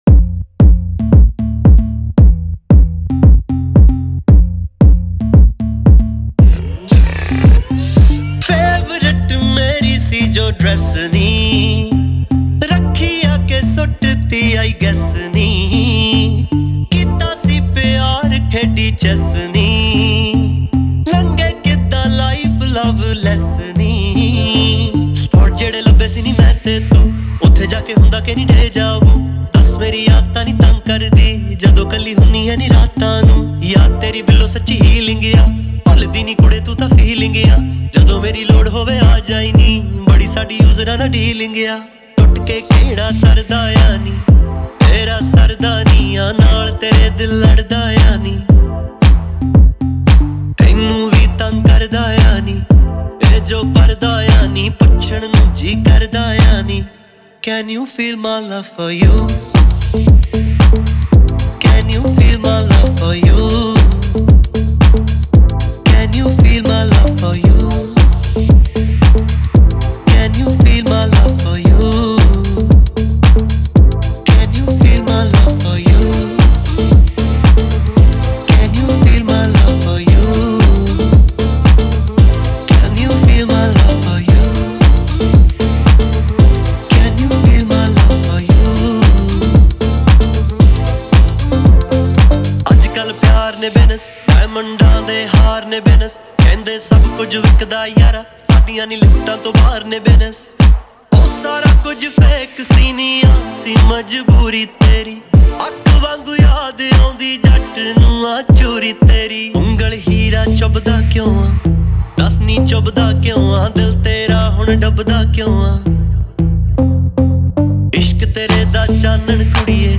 2. Punjabi Songs